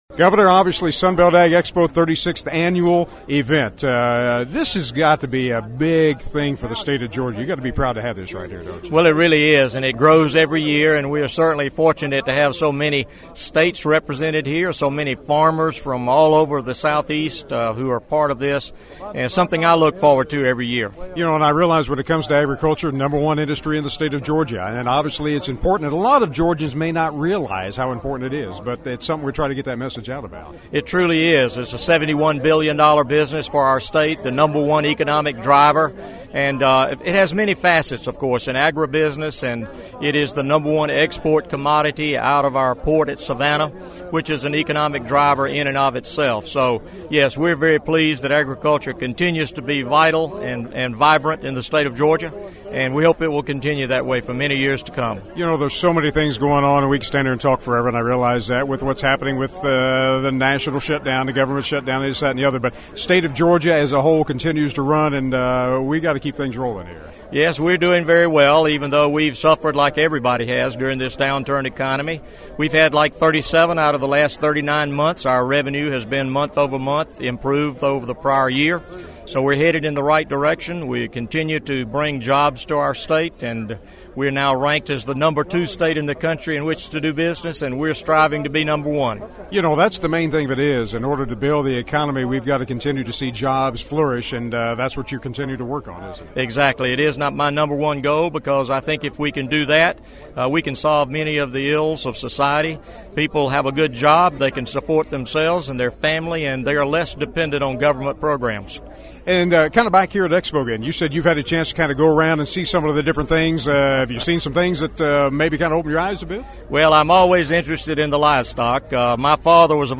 Many folks from across the Southeast were in Moultrie, Georgia for the first day of the 36th annual Sunbelt Ag Expo. One of those was Georgia Governor Nathan Deal who I was able to catch up with and discuss some of the issues important to agriculture and citizens of the state.